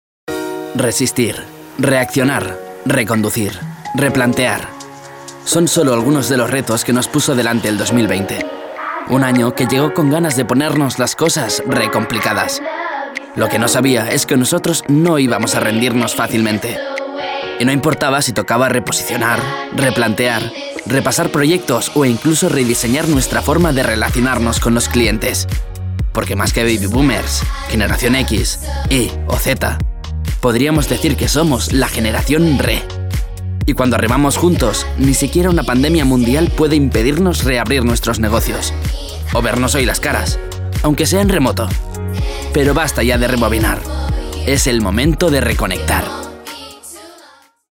sehr variabel
Jung (18-30)
Eigene Sprecherkabine
Commercial (Werbung)